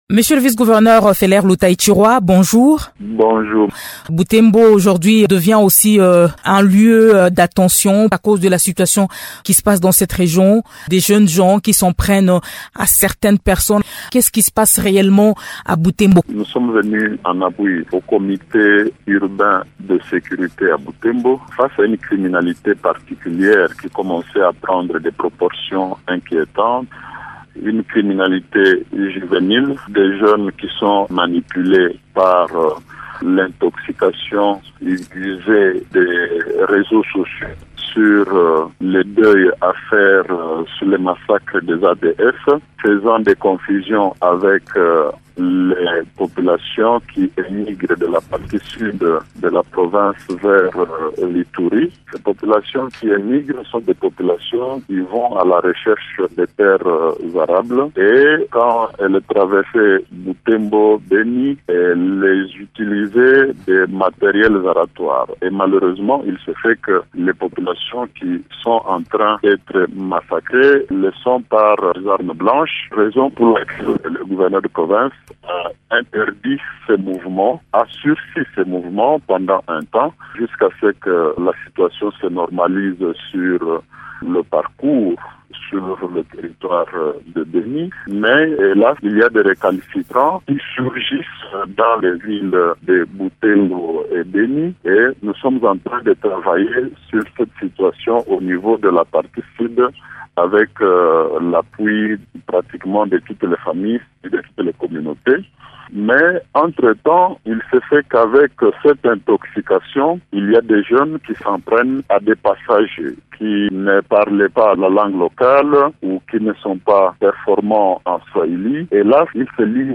Invité de Radio Okapi ce vendredi, le vice-gouverneur du Nord-Kivu Feller Lutahichirwa, en séjour dans la ville depuis mardi, a estimé que ces actes criminels résultent d’un déferlement de passion liée aux massacres à répétition des civils par des ADF dans la région.